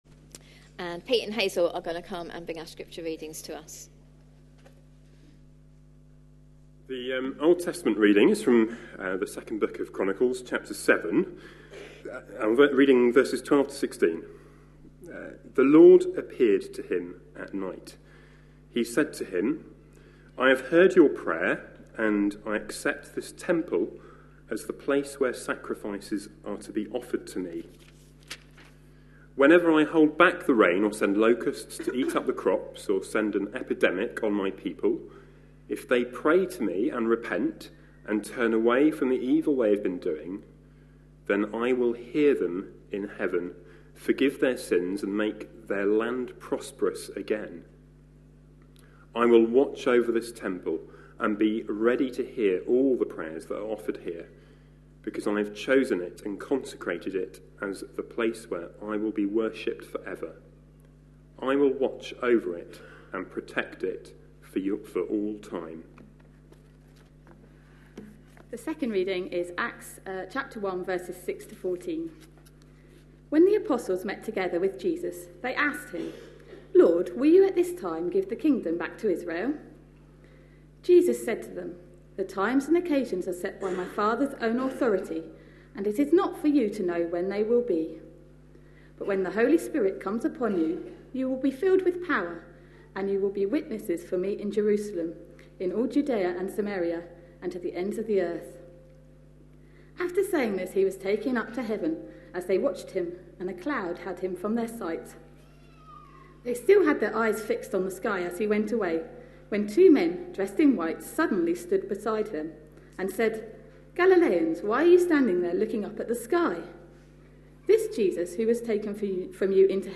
A sermon preached on 10th January, 2016.